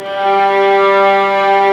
Index of /90_sSampleCDs/Roland LCDP13 String Sections/STR_Violas II/STR_Vas4 Amb p